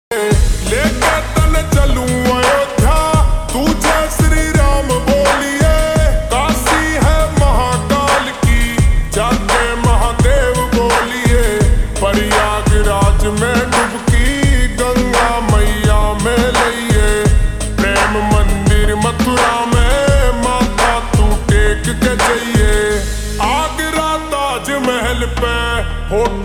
Haryanvi Songs
(Slowed + Reverb)